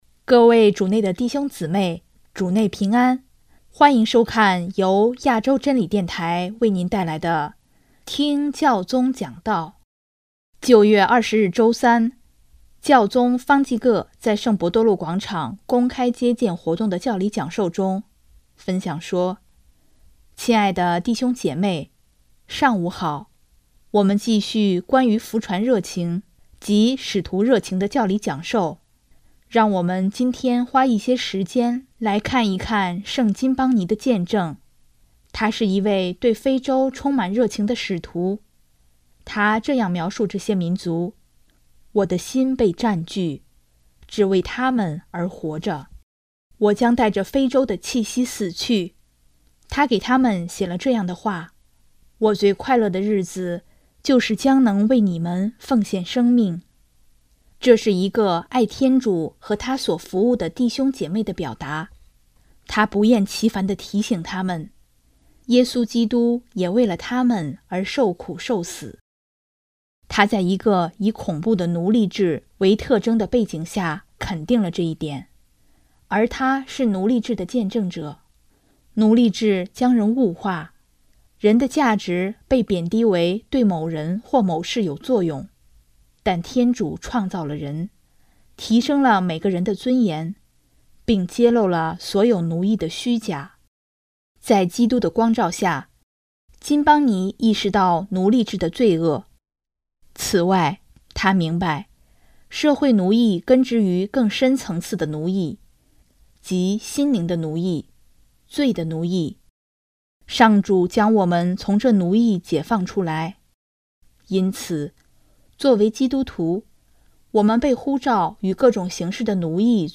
9月20日周三，教宗方济各在圣伯多禄广场公开接见活动的教理讲授中，分享说：